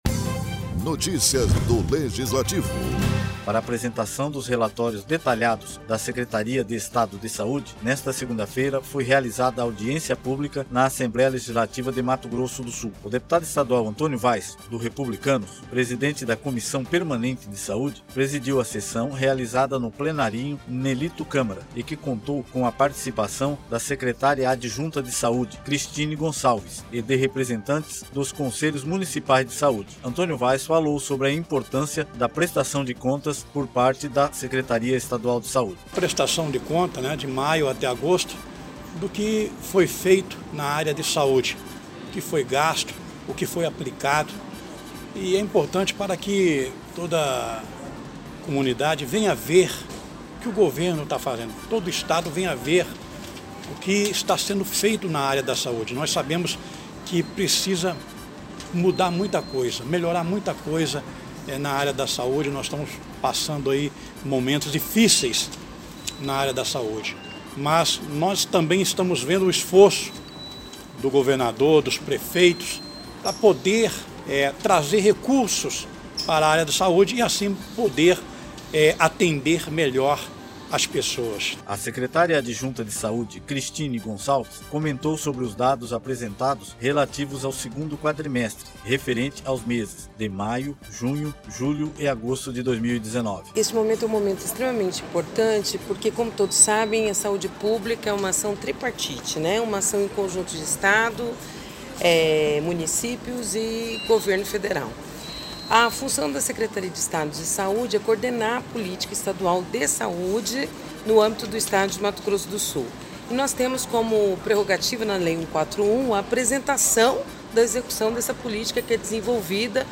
Para apresentação dos relatórios detalhados da Secretaria de Estado de Saúde (SES) nesta segunda-feira (30), foi realizada audiência pública na Assembleia Legislativa de Mato Grosso do Sul.